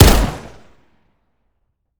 fire-sup-01.ogg